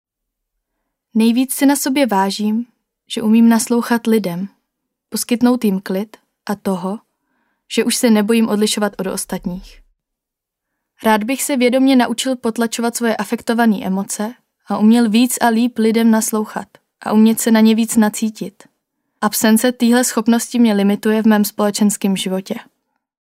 Výpovědi k tématu naslouchání: